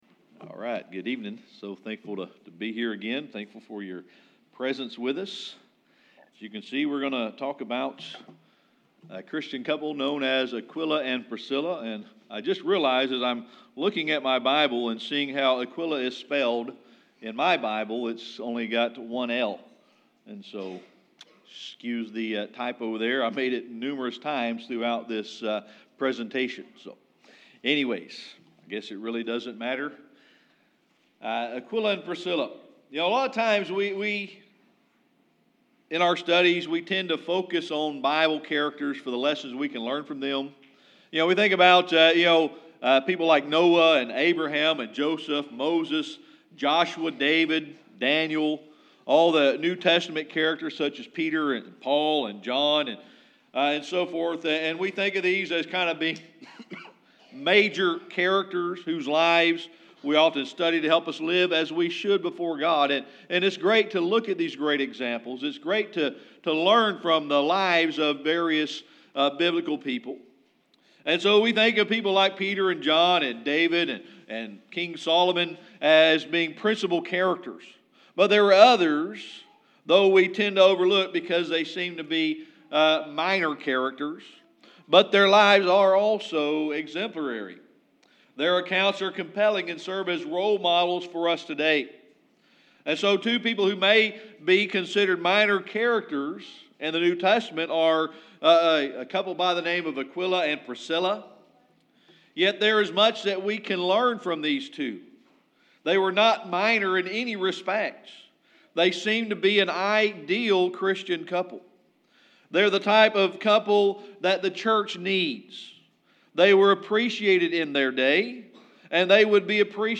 Acts 18:1-3 Service Type: Sunday Evening Worship We're going to talk this evening about Aquila and Priscilla.